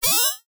It uses an FM Synth that I wrote (Phase Modulation, more accurately) that has a carrier/modulator pair, an LFO (for either pitch or volume uses) and some frequency sweep options and an ASR envelope.
powerup.wav